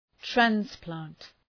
Προφορά
{træns’plænt}